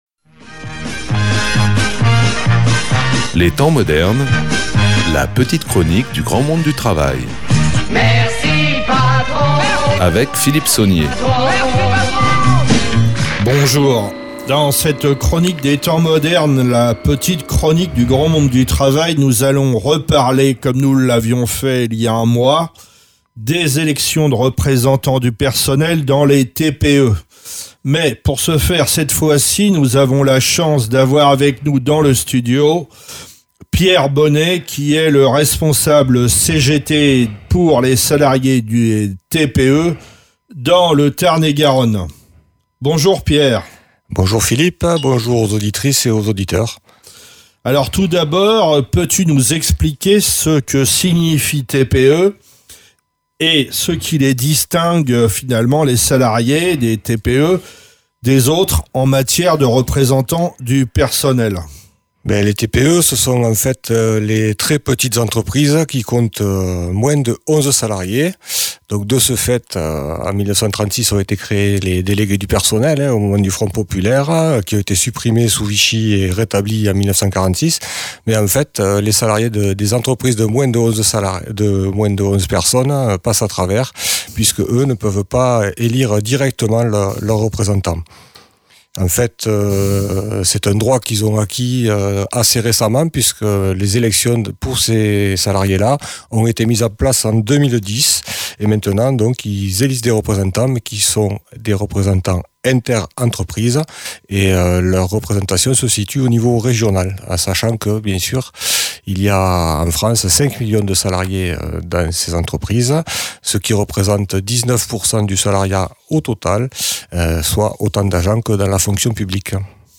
Un interview sur l’importance des élections en cours pour obtenir des délégués.